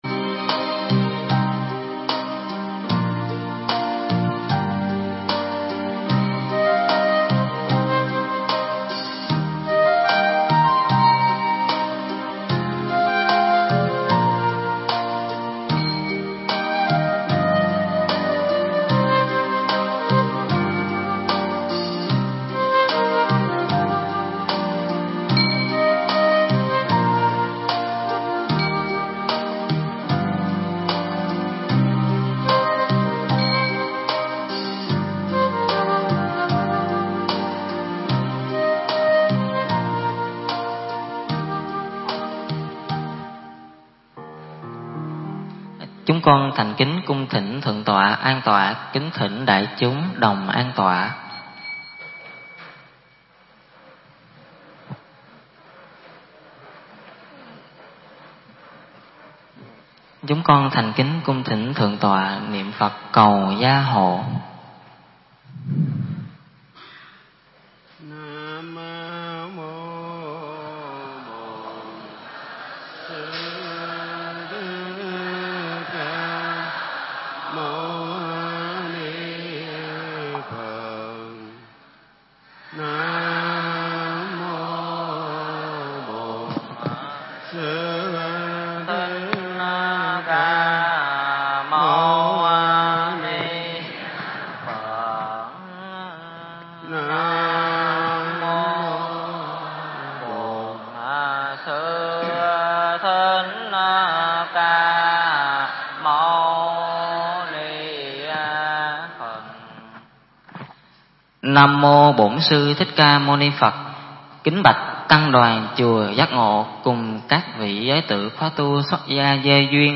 Mp3 Pháp Thoại Trích giảng Kinh Pháp Cú câu 9 và 10
giảng tại Chùa Giác Ngộ Quận 10, TPHCM trong khóa tu xuất gia gieo duyên 2018